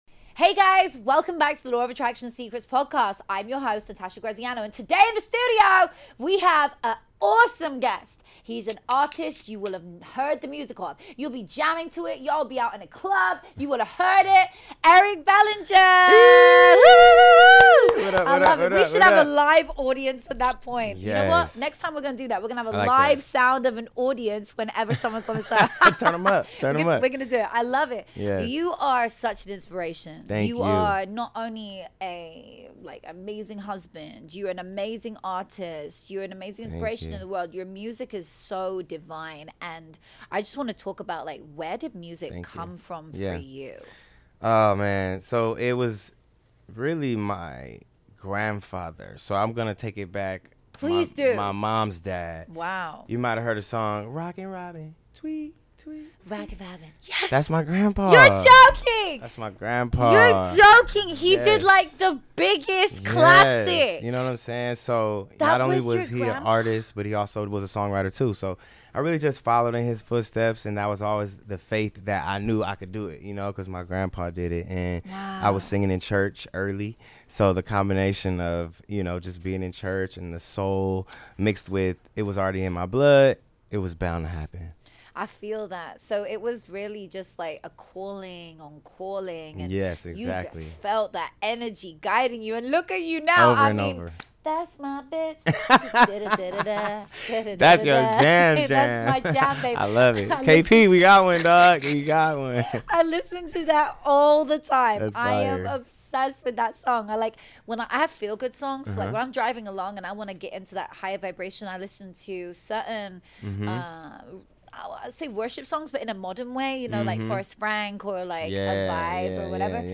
Hey fam, this week on The Law of Attraction Secrets podcast, I sat down with the incredible Eric Bellinger — Grammy-winning artist, songwriter, and one of the most inspiring voices in music today.